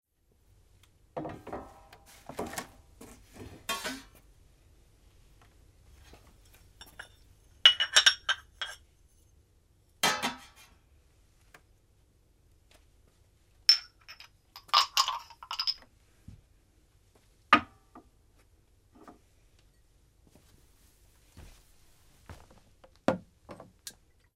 Colocar vasos y tazas sobre una bandeja de metal
vajilla
metal
Sonidos: Acciones humanas
Sonidos: Hogar